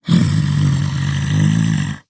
sounds / monsters / fracture / idle_0.ogg